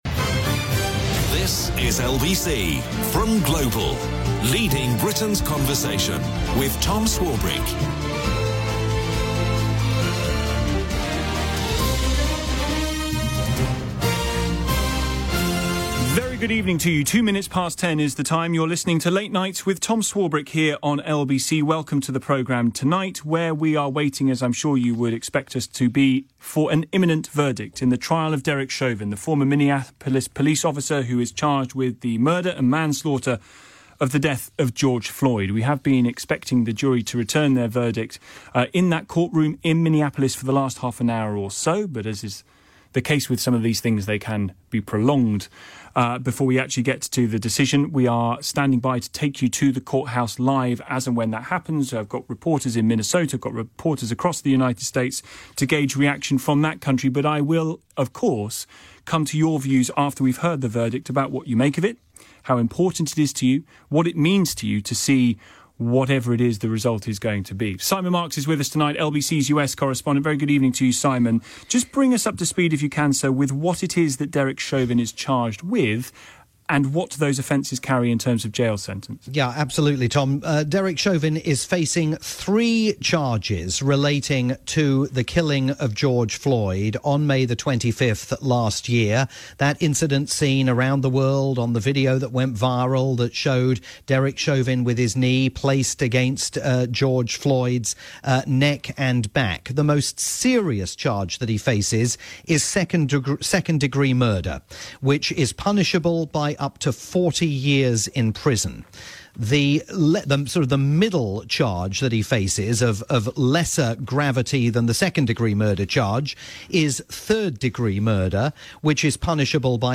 continuing live coverage for LBC